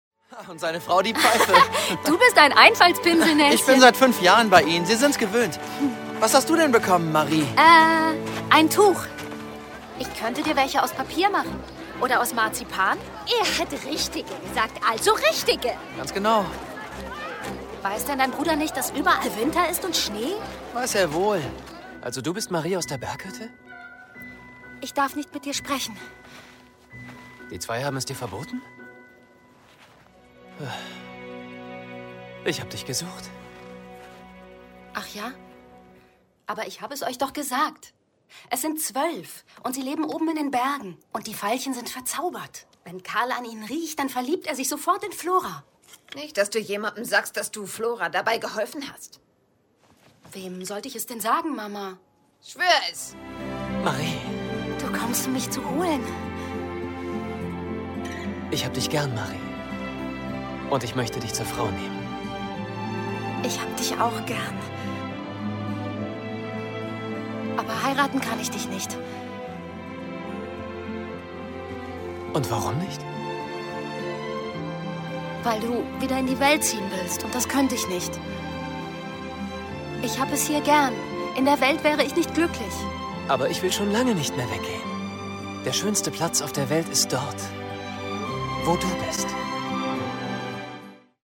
Sprachproben